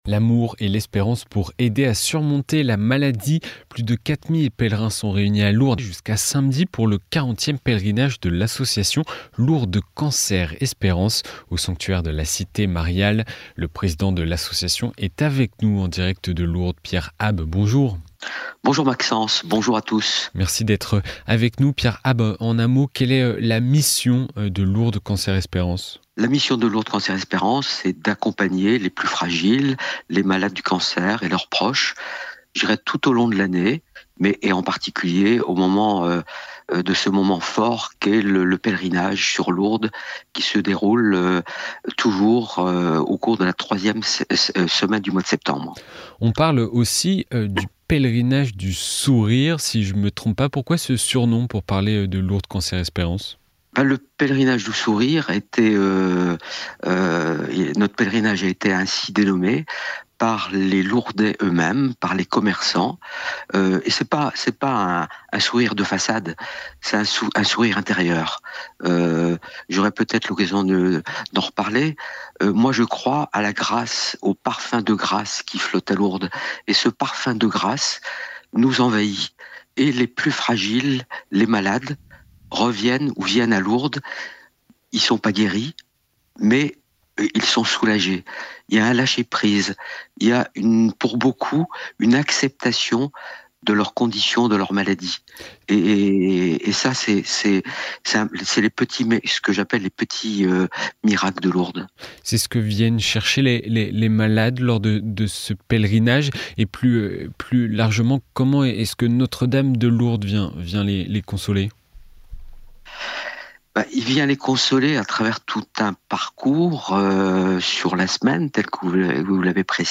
répond au micro de KTO Radio dans L’invité de la Matinale.